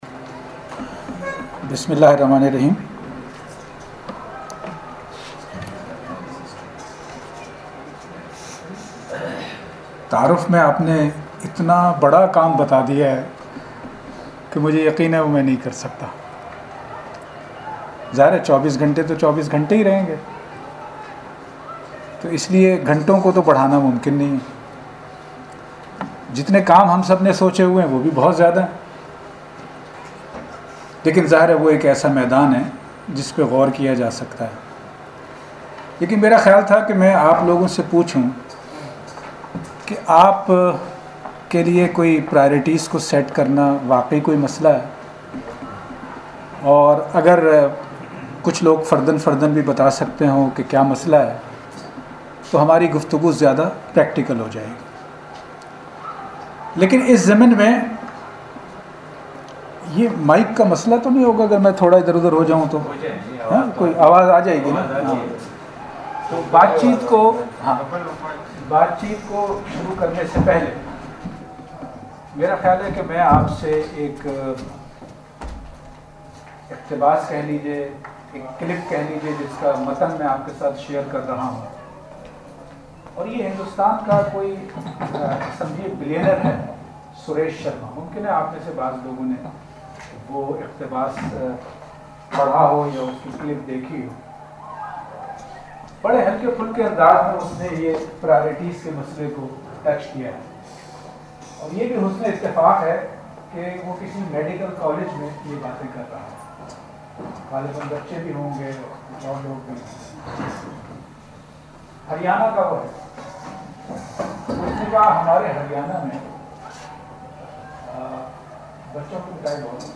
Training Workshop at PMC – February 2017 – Audio Recording